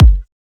KICK WTCA.wav